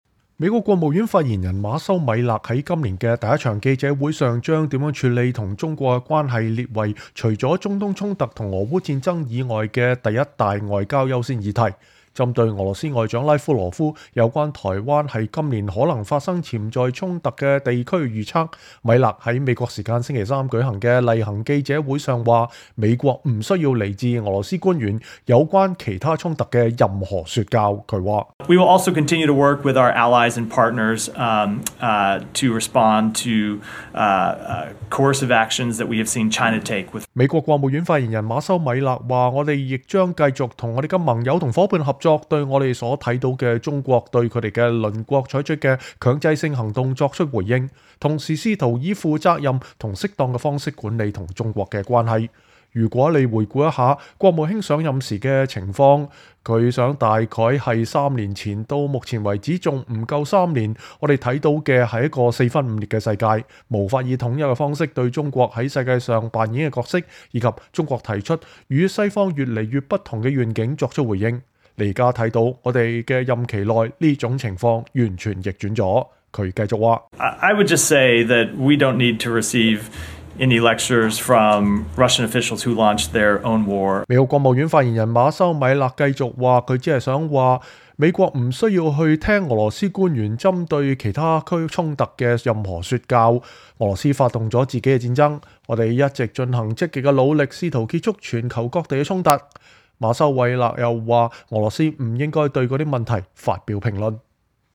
在今年的首場記者會上把如何處理與中國的關係列為除中東衝突和俄烏戰爭以外的一大外交優先議題。